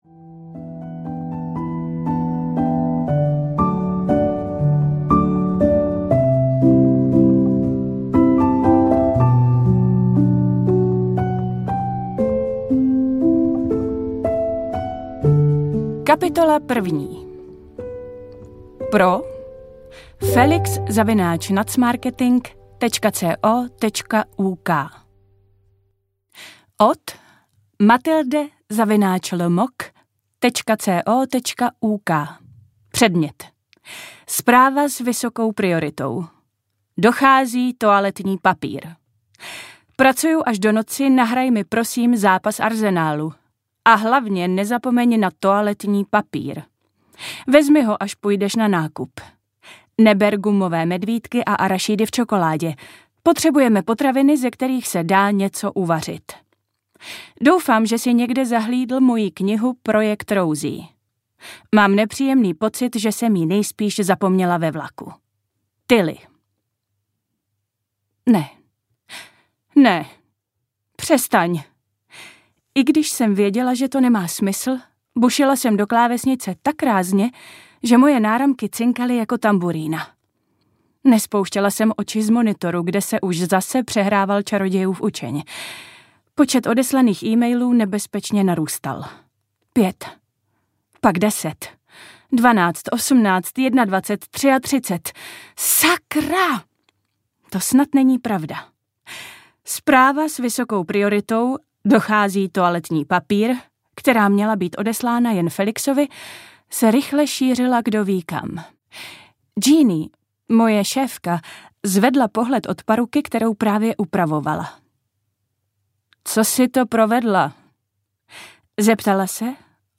S láskou z Londýna audiokniha
Ukázka z knihy
• InterpretAnna Fixová